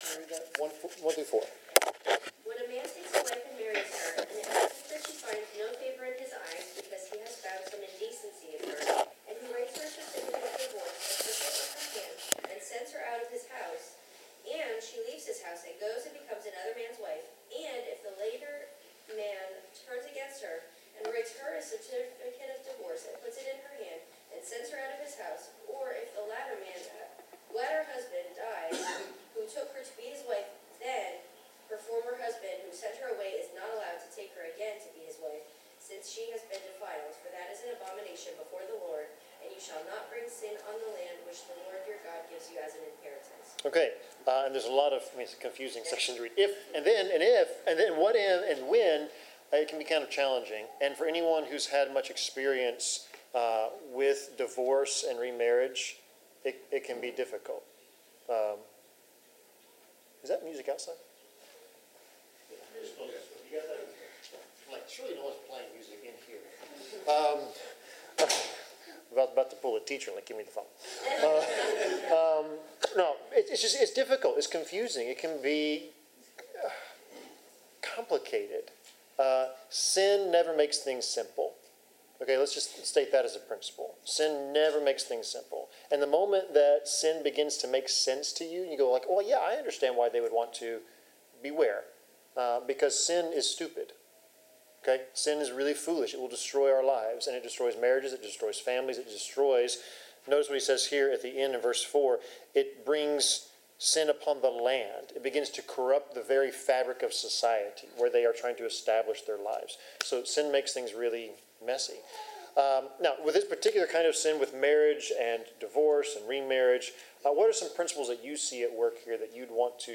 Bible class: Deuteronomy 24
Passage: Deuteronomy 24:1-9 Service Type: Bible Class